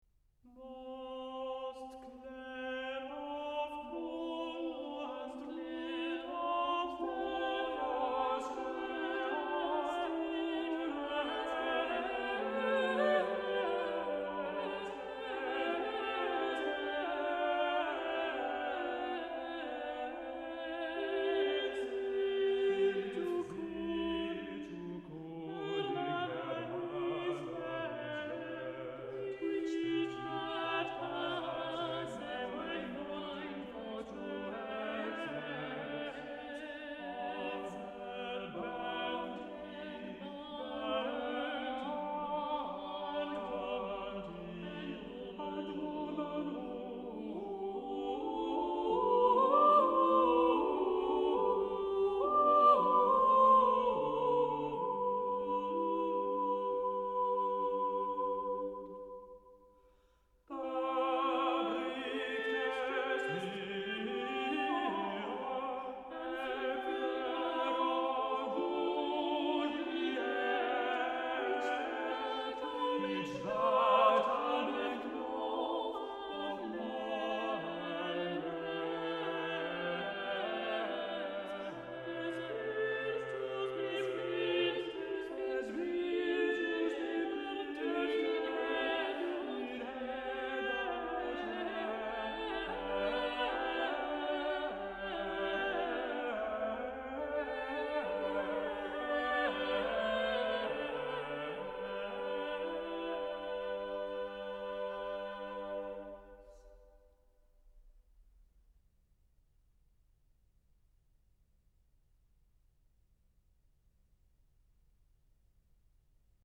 The largest collection of English sacred music composed during the late fifteenth century, the Choirbook is something we’re very lucky to have.